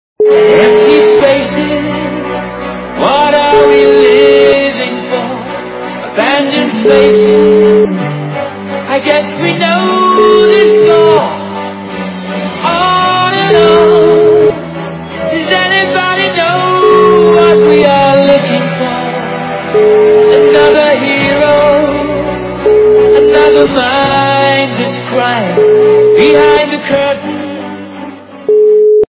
западная эстрада
При заказе вы получаете реалтон без искажений.